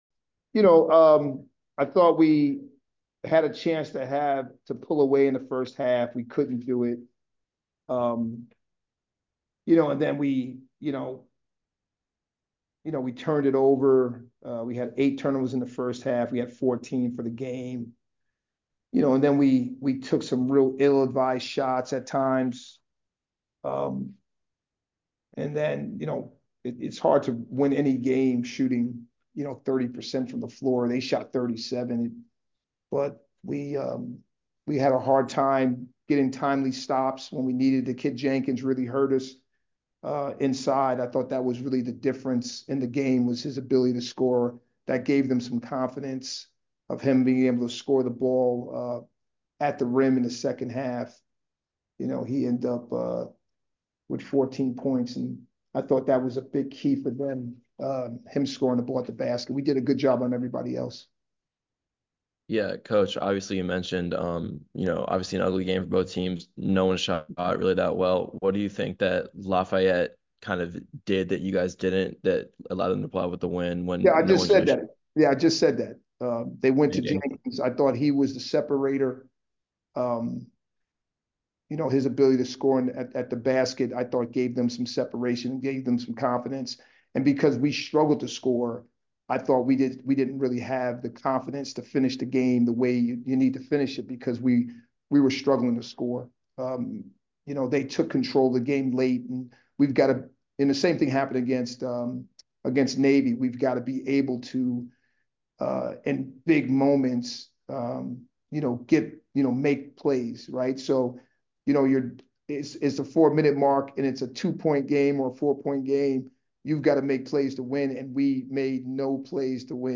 Lafayette Postgame Interview (1-6-24)